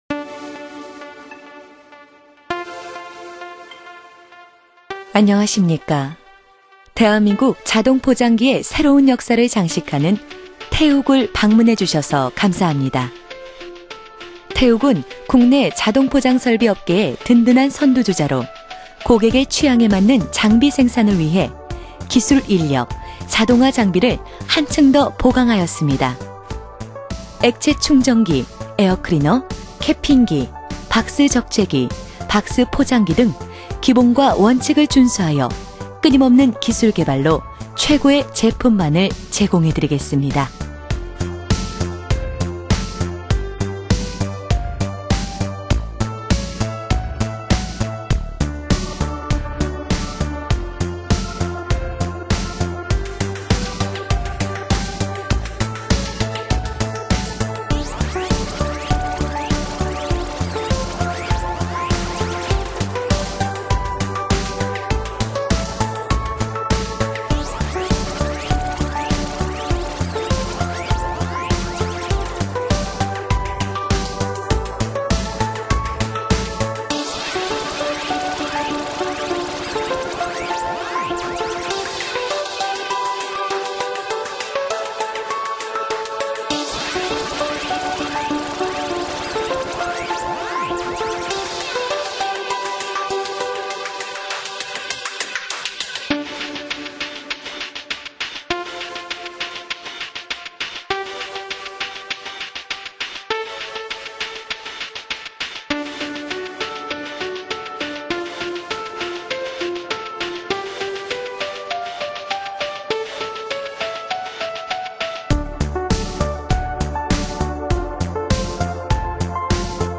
대표 인사말
회사음성소개.bgm_3.mp3